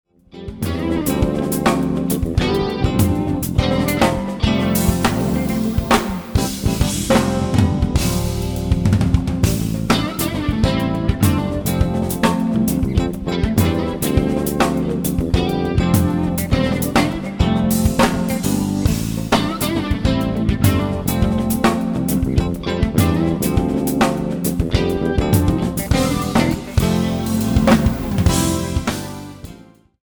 A play-along track in the style of fusion.